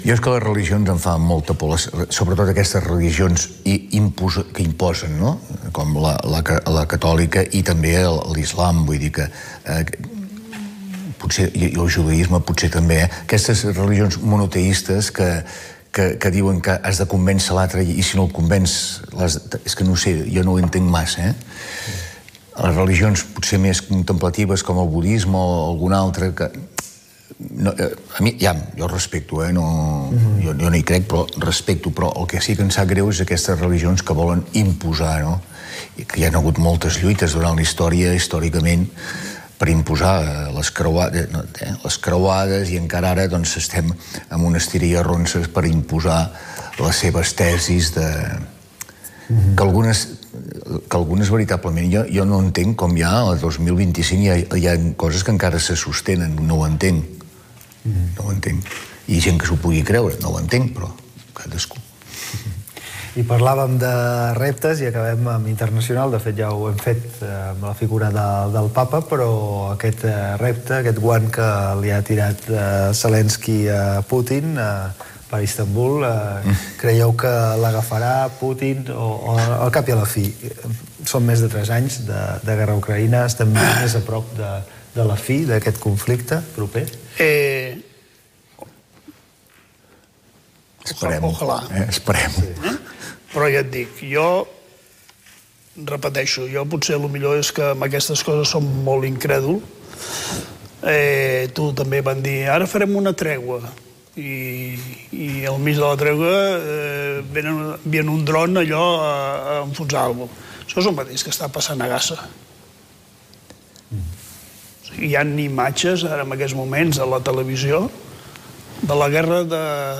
La tertúlia del magazín
FM